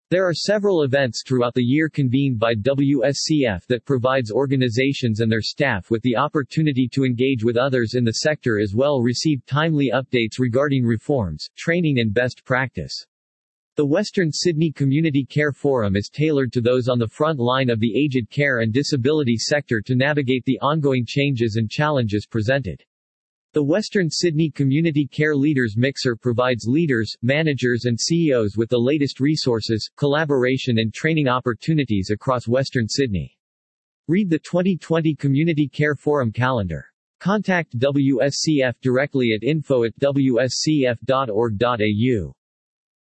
Text to speech